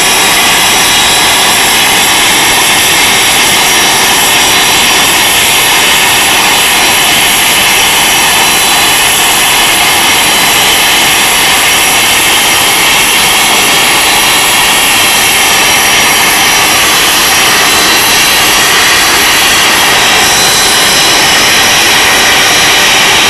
hawk-whine.wav